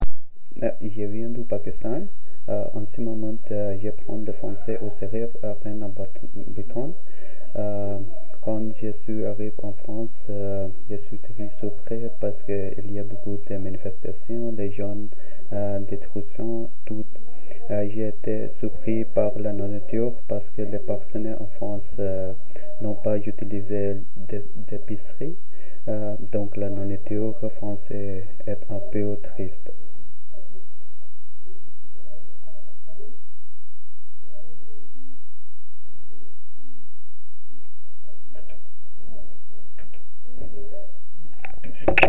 Cabine de témoignages
Témoignage du 24 novembre 2025 à 16h57